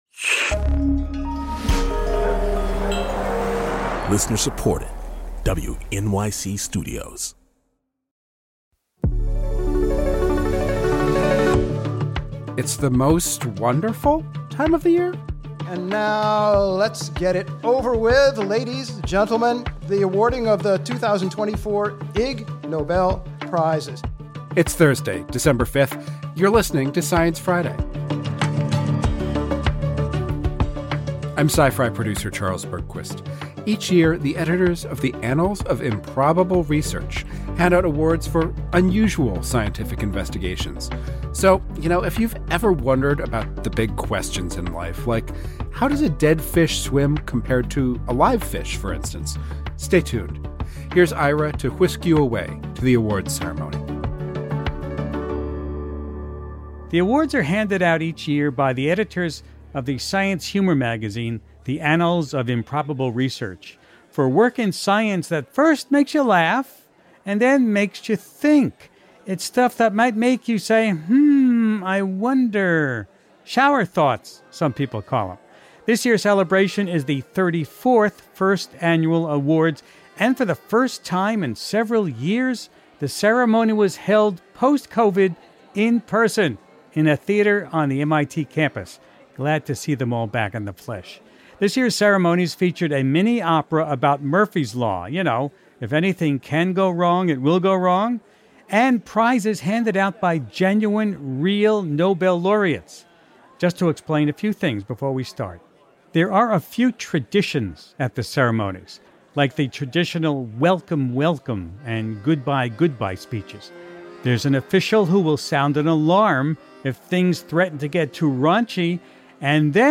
This year’s awards were presented in a theatrical extravaganza in an MIT lecture hall in September.
In a Science Friday holiday tradition, Ira hosts an hour of highlights from the ceremony.